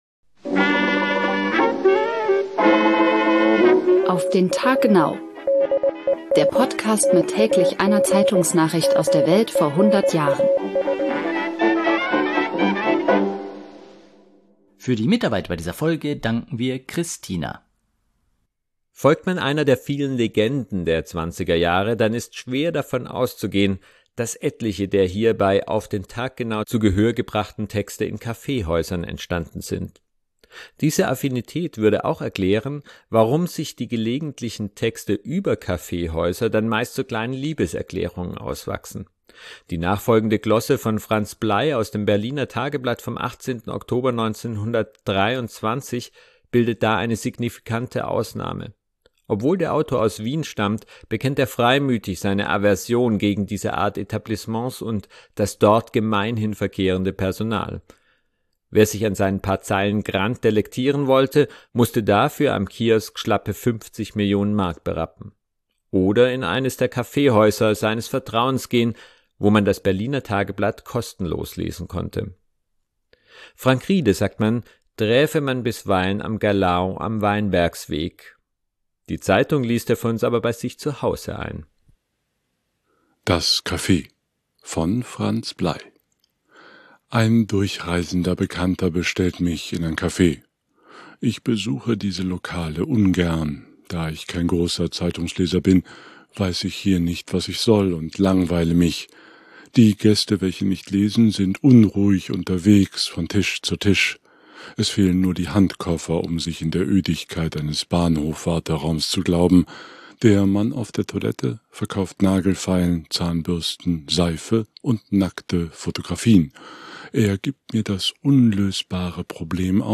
Zeitung liest er für uns aber bei sich zu Hause ein.